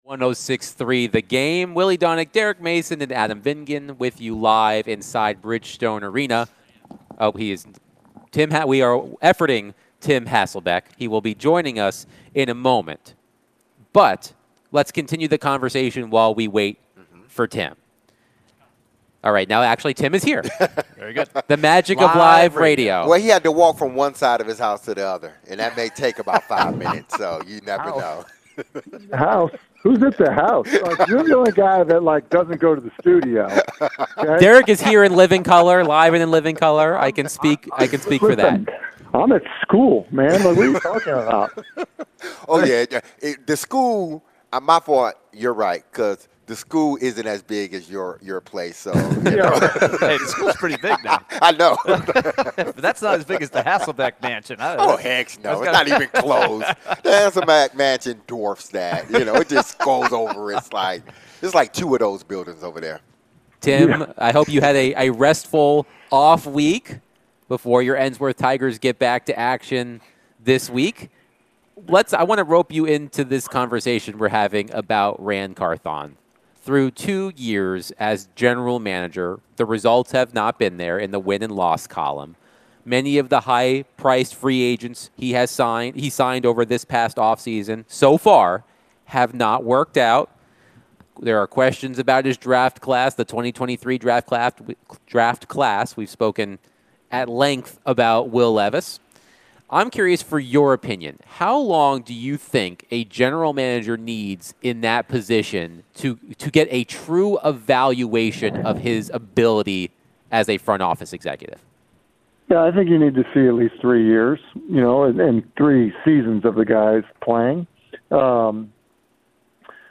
ESPN NFL Analyst Tim Hasselbeck joined the show to discuss the Titans' embarrassing loss to the Bills on Sunday. What does Tim think about the rest of the Titans' season?